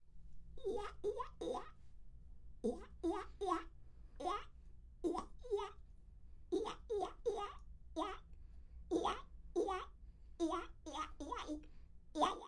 描述：模仿鸭子或企鹅的慢速度
Tag: 企鹅 鸭子